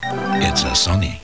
音を聴きながら図を見ていると、波形の大きさと音の強弱が合っている様子がわかりますね。